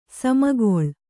♪ samakoḷ